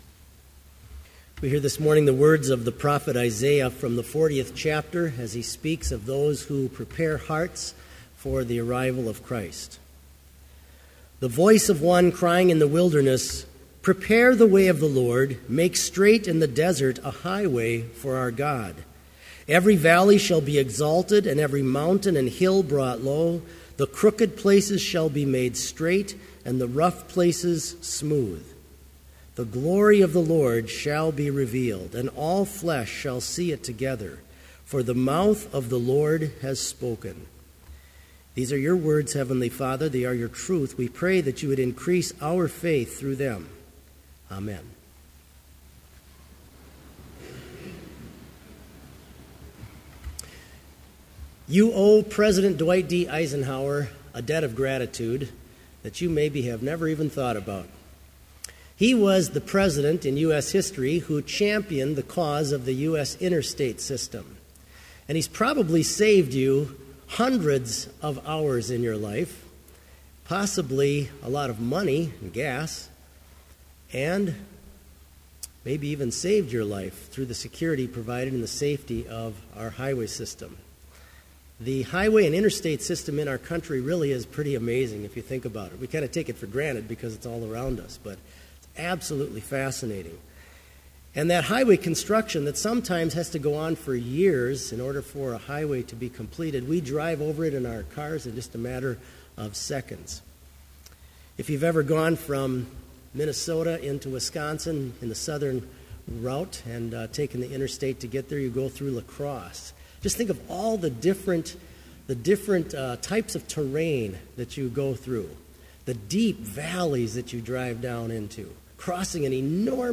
Complete service audio for Chapel - December 5, 2014